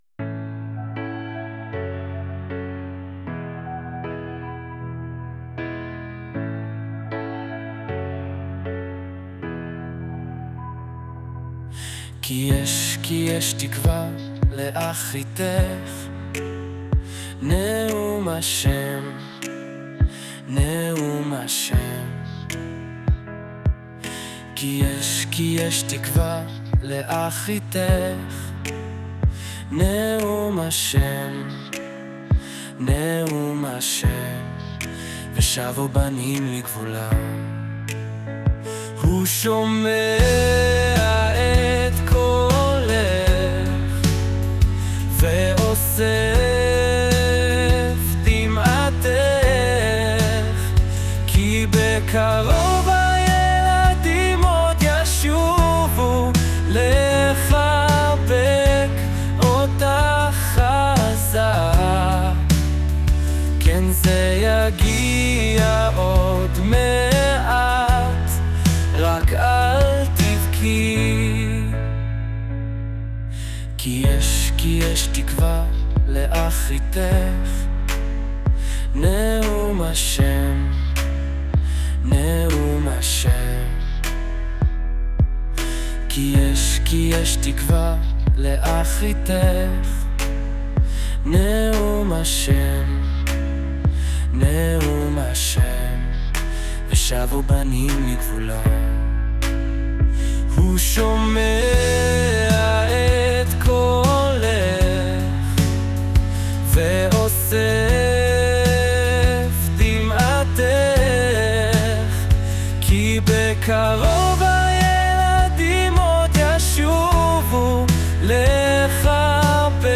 שיר מרגש ומלא תקווה